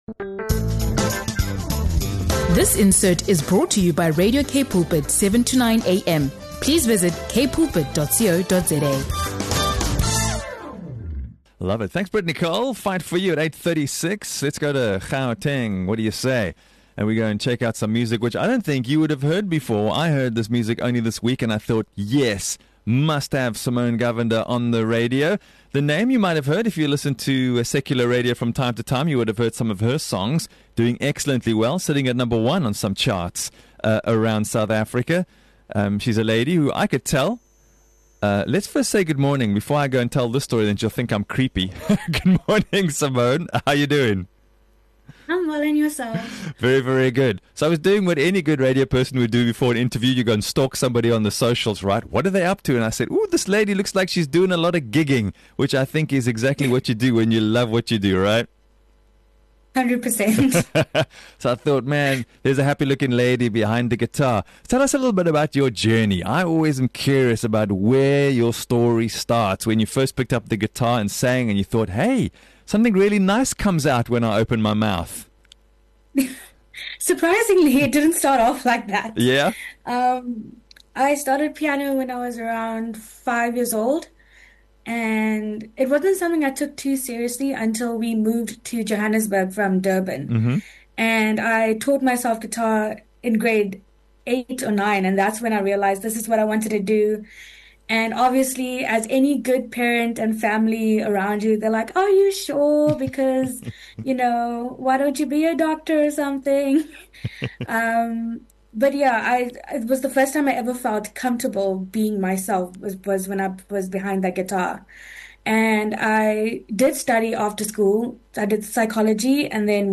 In this inspiring podcast episode, we sit down with South African singer-songwriter
This honest conversation explores: - Mental health, vulnerability, and creative block - Writing music that brings hope and encouragement - Faith, purpose, and authenticity in art - The emotional power of 80s & 90s music and artists like Linkin Park - What it means to be a modern South African artist on the rise If you’re feeling stuck, overwhelmed, or in need of a musical pick-me-up, this episode is for you.